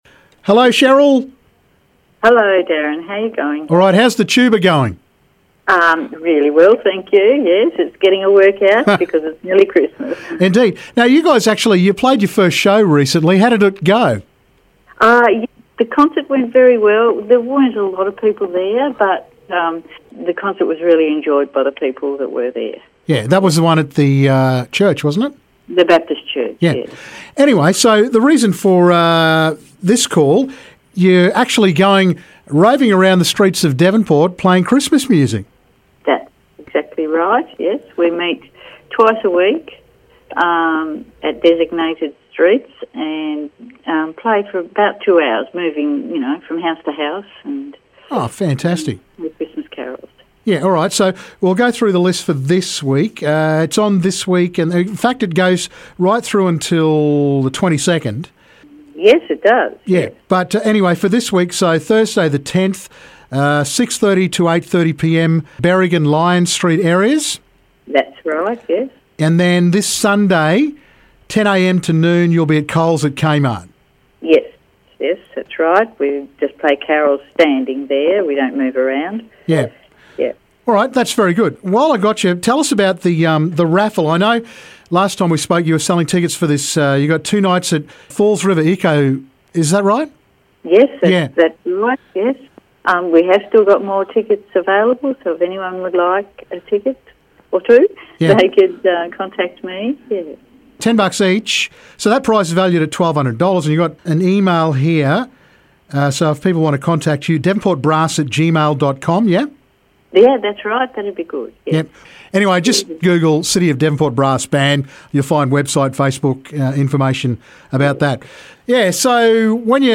Devonport Brass Band Street Carols.
The Devonport Brass Band are doing Street Carols.